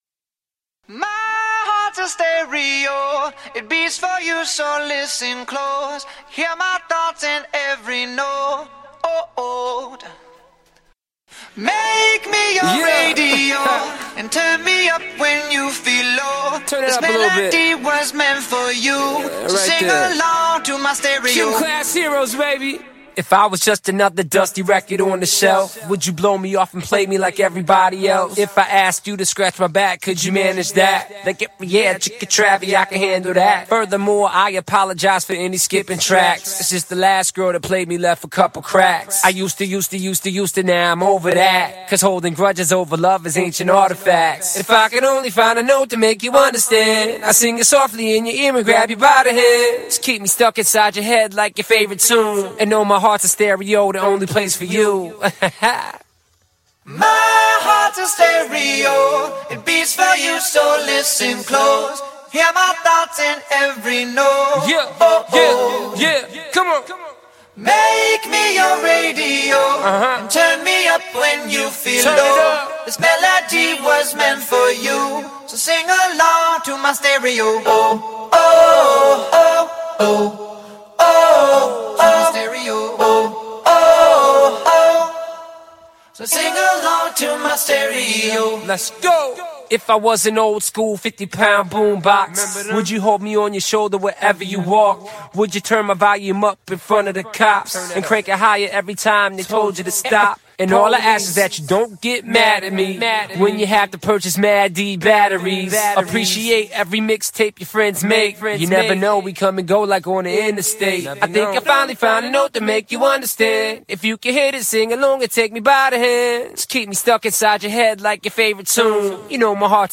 Скачать Зарубежные акапеллы [150]